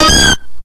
Audio / SE / Cries / IGGLYBUFF.ogg